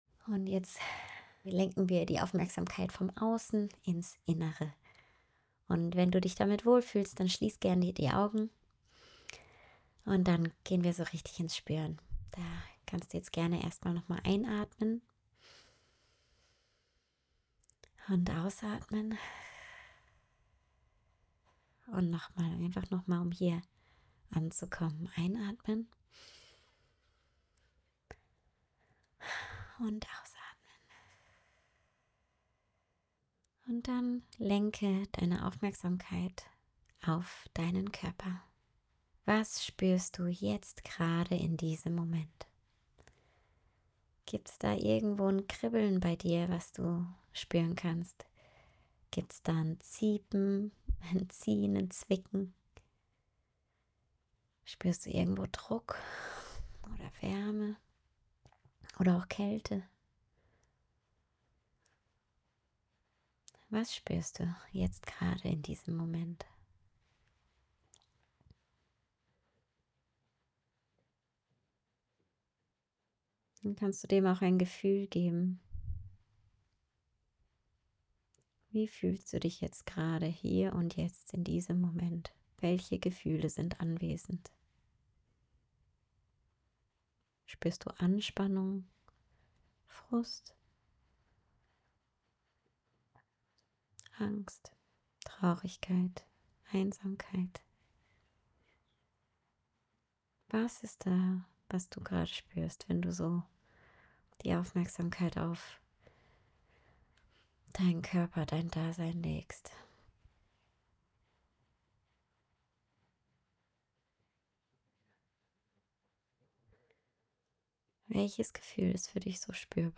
2. Der 3-Minuten-SOS-Empathie-Audioguide